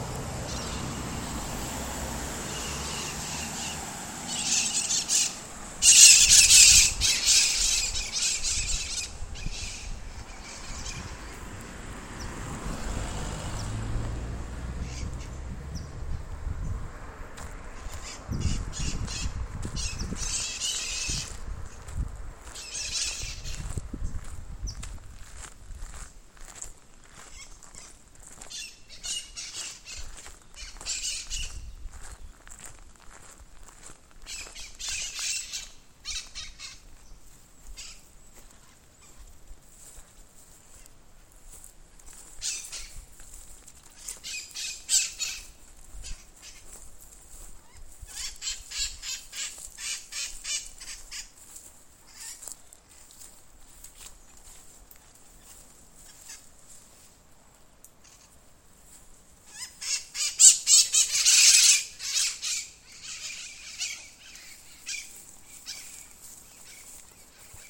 Mitred Parakeet (Psittacara mitratus)
Detailed location: Santa lucia
Condition: Wild
Certainty: Recorded vocal